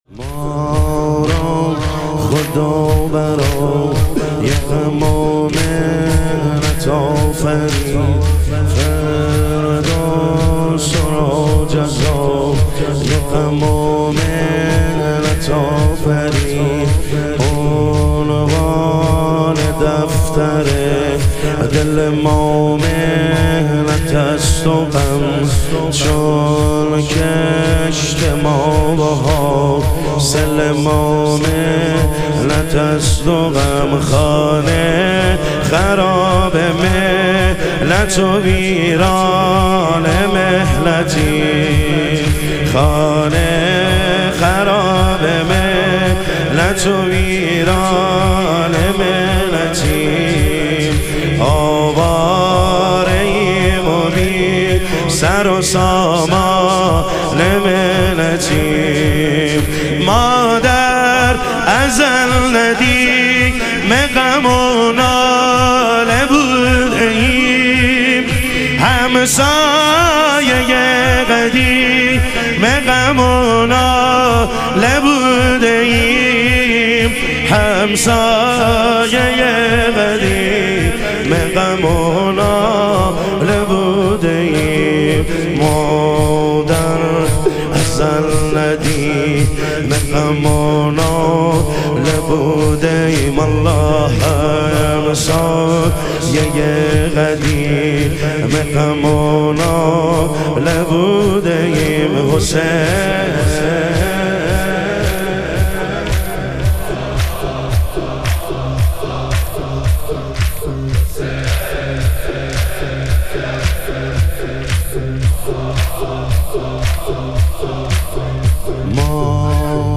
شب شهادت حضرت ام البنین علیها سلام